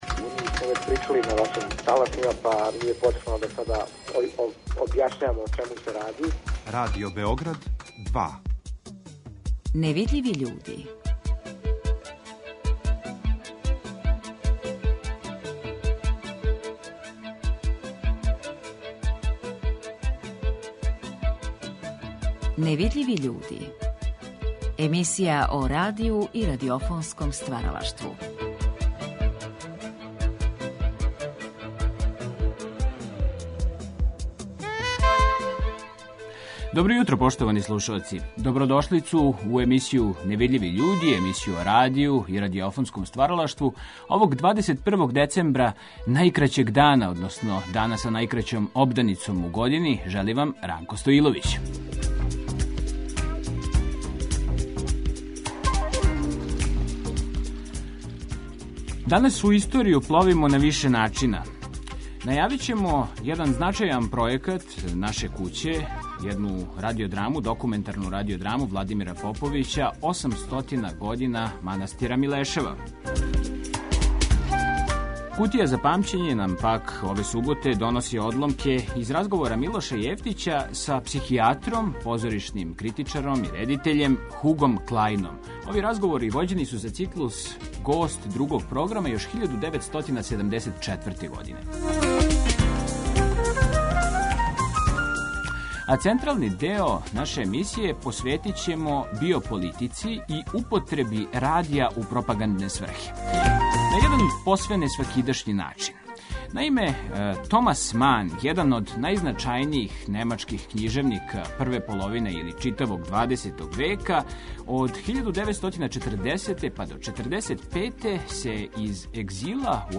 Моћи ћете да чујете и оригиналне звучне записе Манових обраћања.